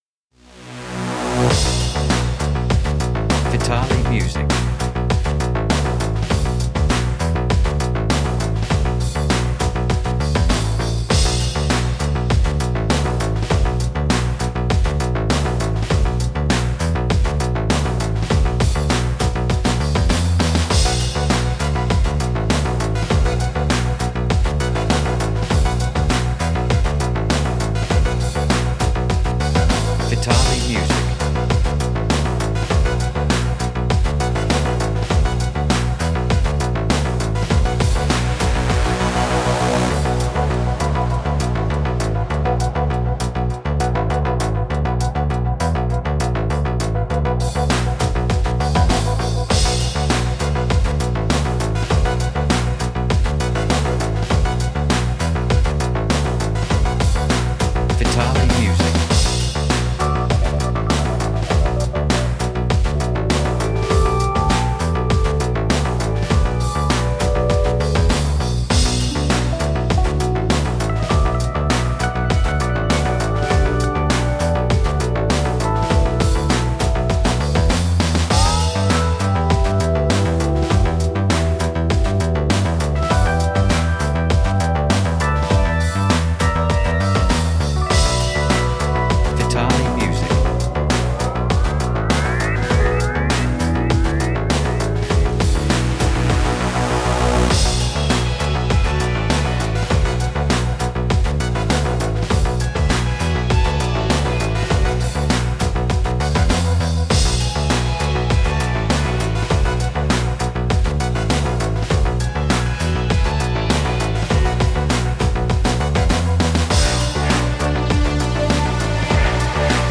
Так я понял что сделал правильный выбор :D:D:D В атаче небольшая проба пира. Кроме эл.пиано, лида и барабанов (разумеется), всё он.